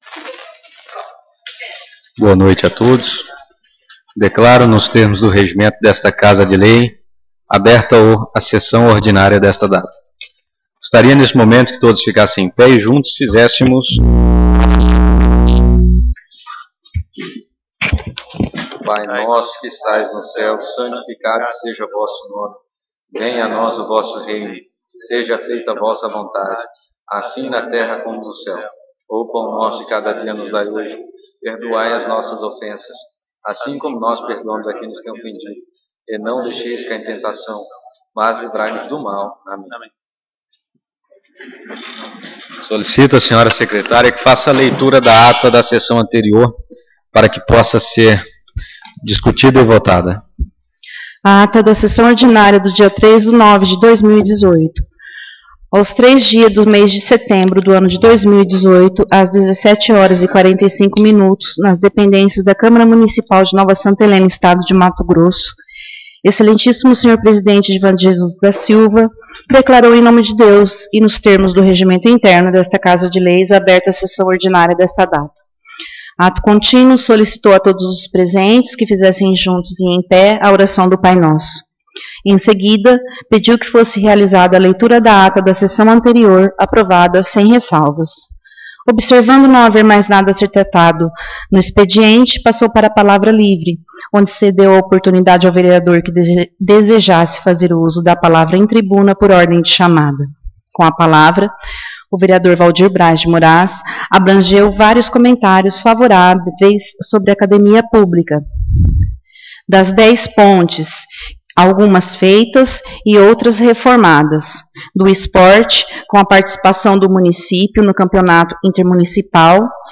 Sessão Ordinária 10/09/2018